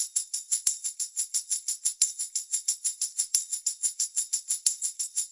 手鼓2小节循环 90BPM 48k Hz
描述：由D'Angelo启发的带有拖动凹槽的2条手鼓环。
Tag: 沟槽 铃鼓 敲击